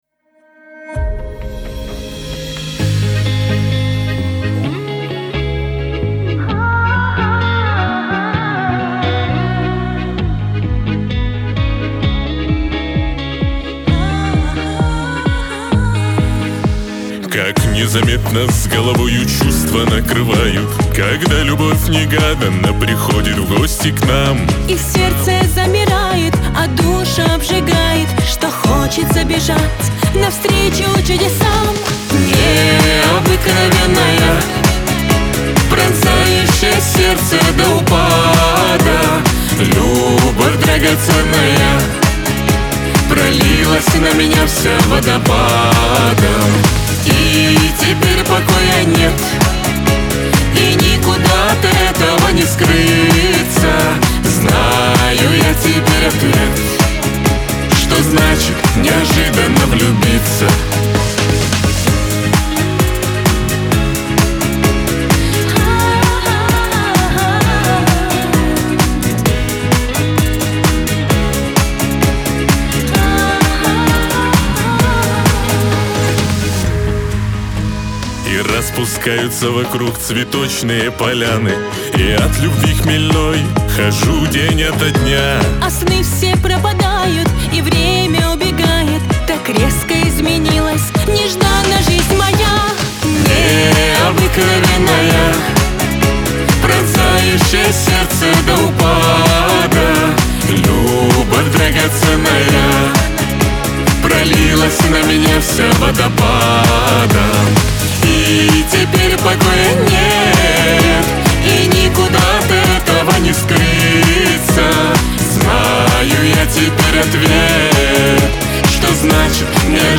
дуэт
Лирика